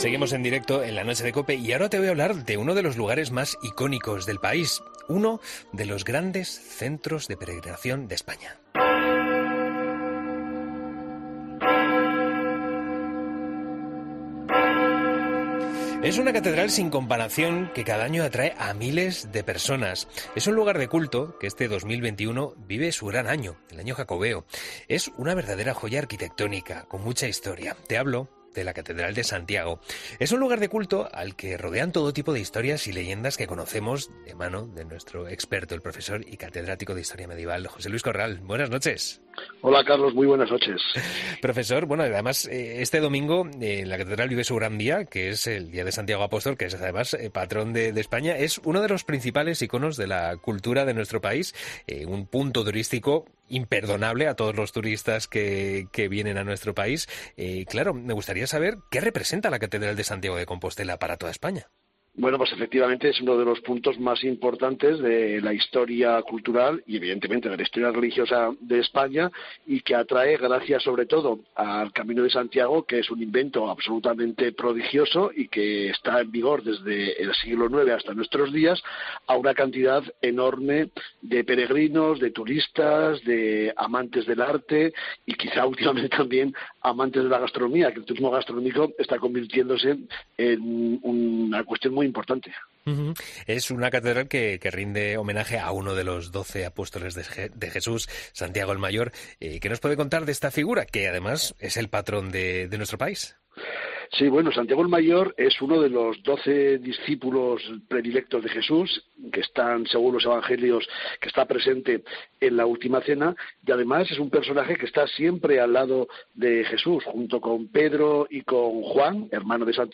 profesor y catedrático de historia medieval